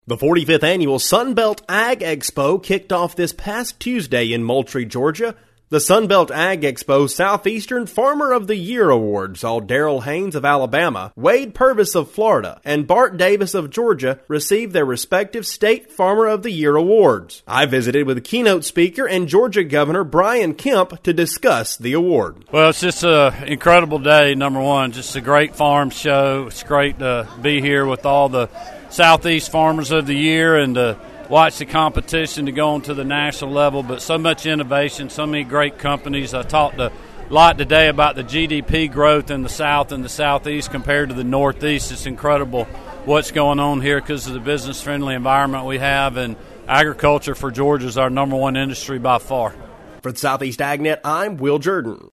Listen below to hear more from Kemp in an exclusive interview with Southeast AgNet.